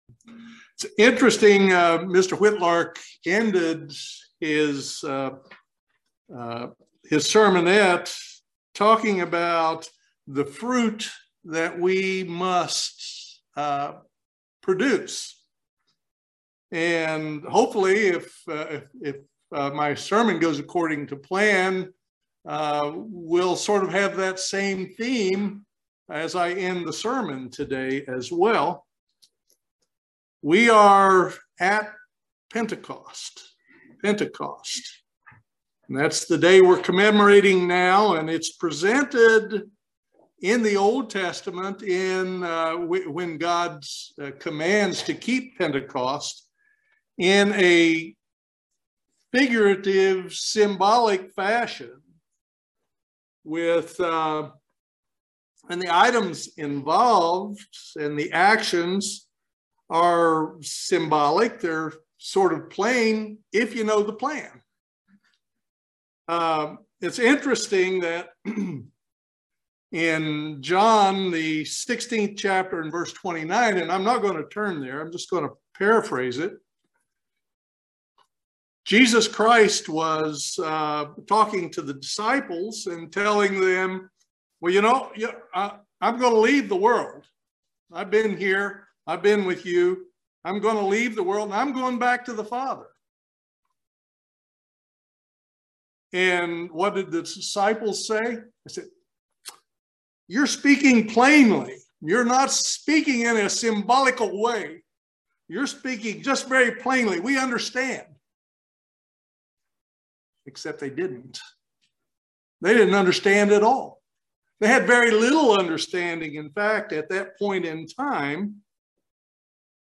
Excellent sermon on the wave sheath offering and Pentecost.